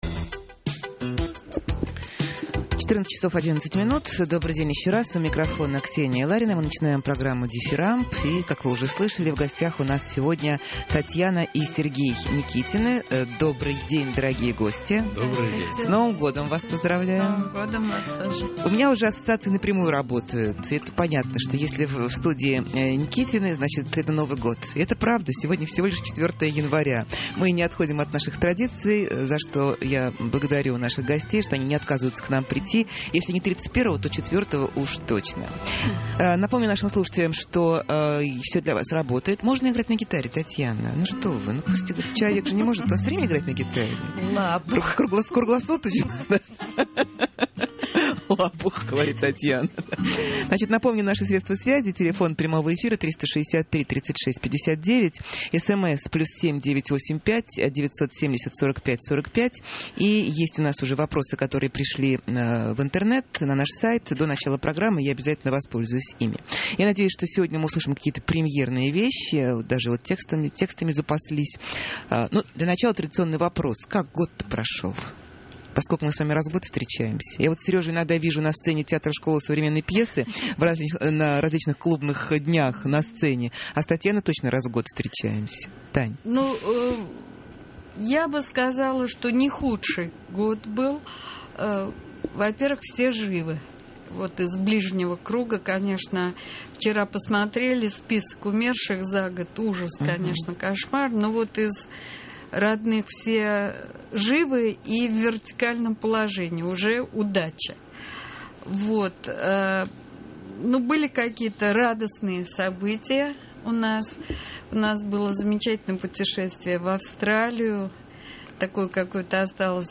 У микрофона Ксения Ларина, начинаем программу «Дифирамб». И, как вы слышали, в гостях у нас сегодня Татьяна и Сергей Никитины.